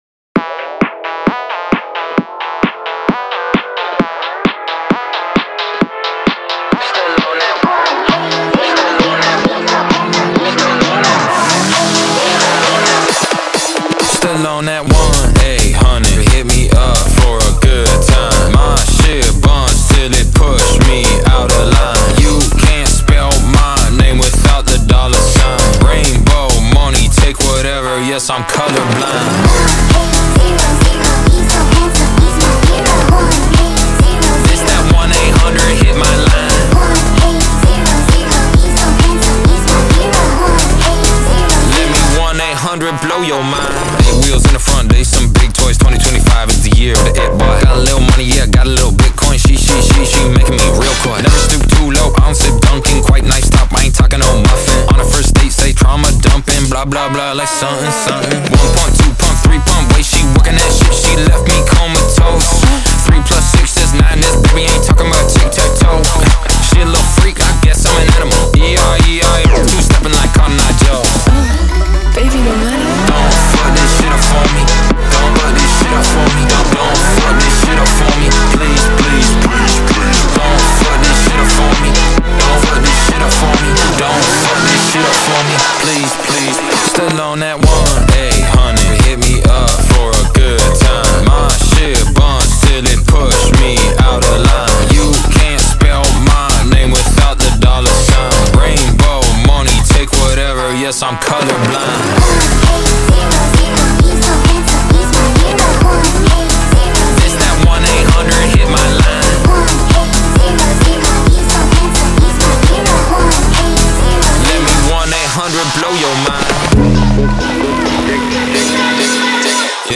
BPM132-132
Audio QualityPerfect (High Quality)
Rap/EDM song for StepMania, ITGmania, Project Outfox
Full Length Song (not arcade length cut)